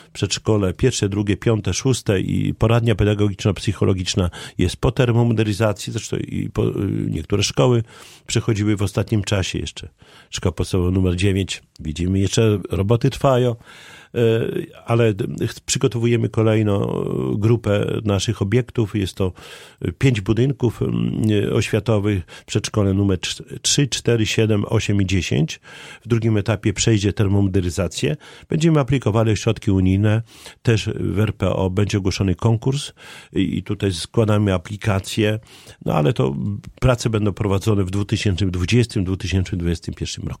– Chcemy, by kolejne suwalskie placówki przeszły identyczne metamorfozy – mówi Czesław Renkiewicz, prezydent Suwałk.